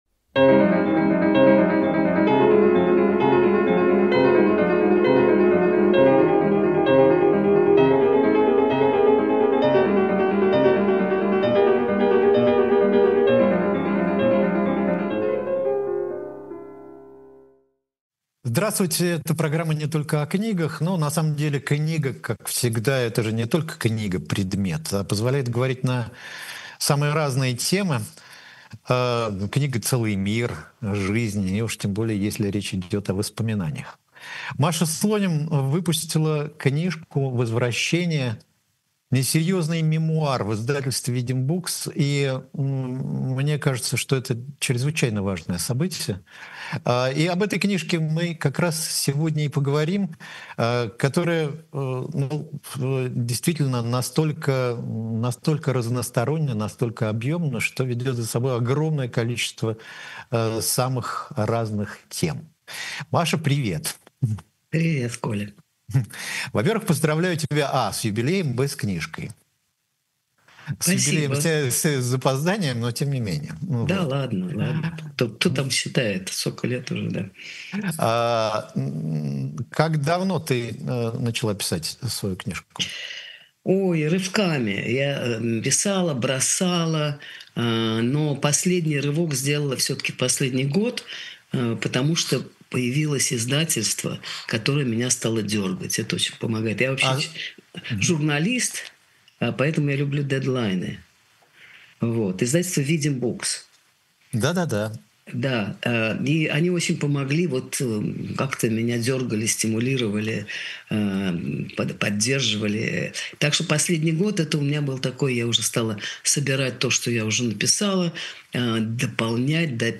Эфир ведёт Николай Александров
— разговор с автором.